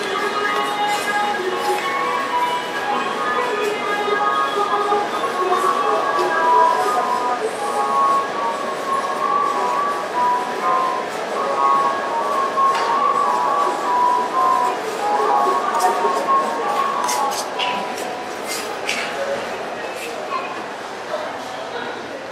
w Pop / Soul
Znalazłem w archiwum filmik z galerii handlowej, nagrany w 2009r. Załączam jedyny fragment audio utworu który jest w miarę słyszalny (akurat jest to końcówka tego utworu).
Słychać śpiew kobiety (pamiętam, że był to dość popularny utwór grany często w radiu w tamtych latach).